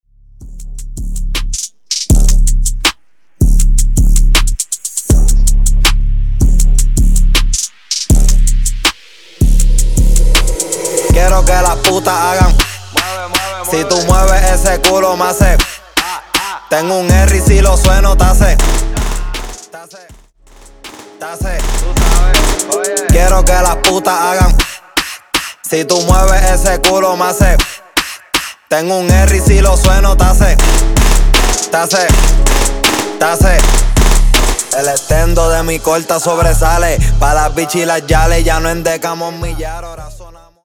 Extended Dirty Coro Tik Tok